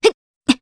Scarlet-Vox_Landing_Jp.wav